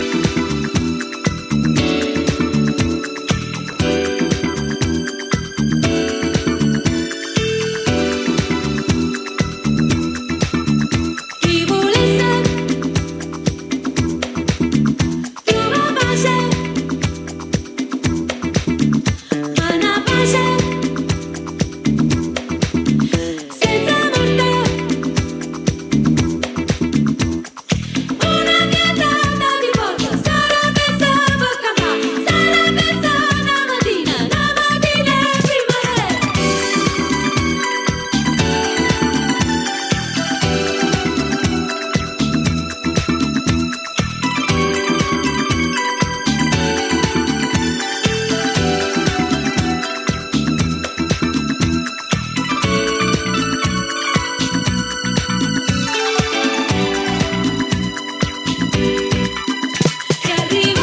disco, jazz-funk, African rhythms